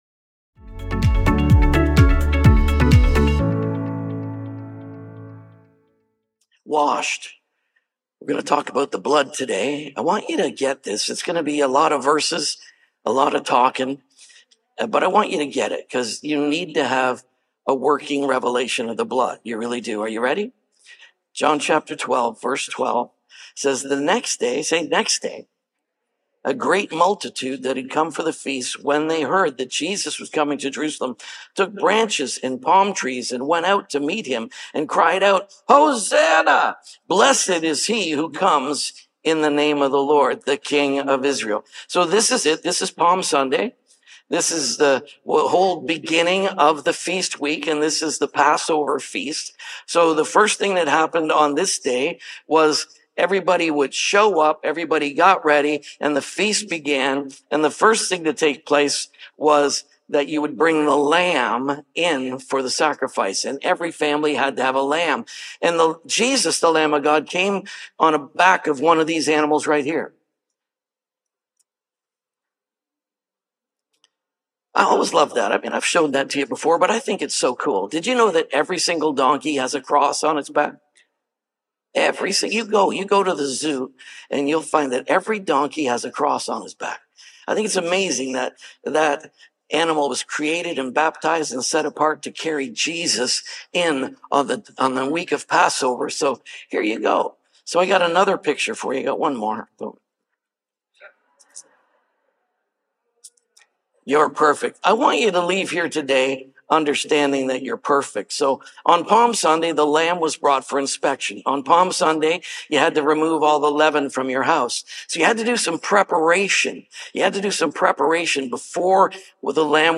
You're-Perfect-|-WASHED-|-SERMON-ONLY.mp3